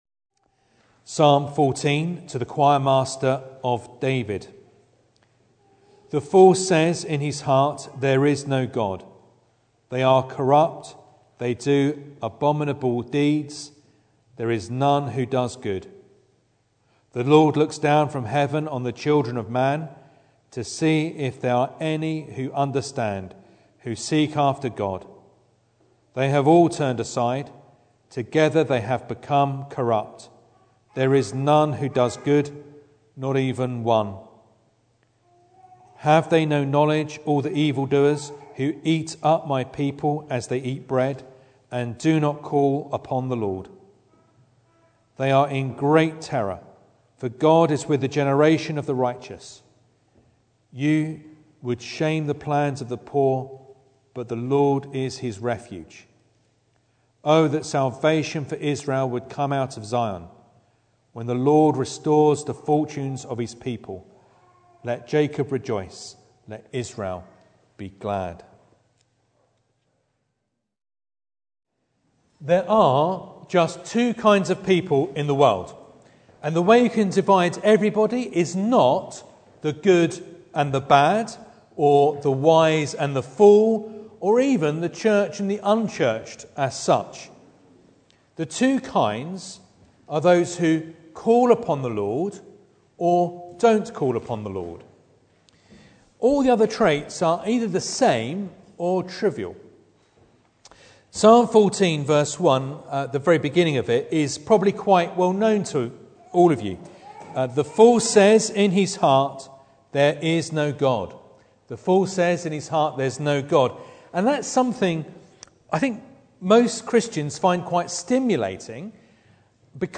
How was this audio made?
Psalm 14 Service Type: Sunday Evening Bible Text